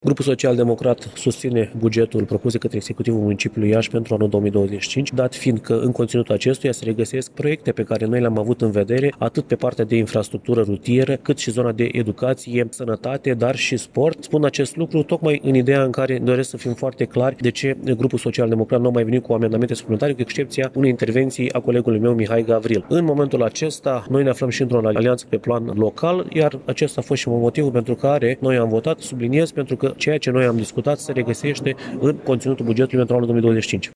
Consilerii social-democrați au votat bugetul Iașiului pe anul în curs, după ce amendamentele lor au fost supuse atenției în comisii și votate ulterior, a declarat liderul grupului PSD, Bogdan Crucianu.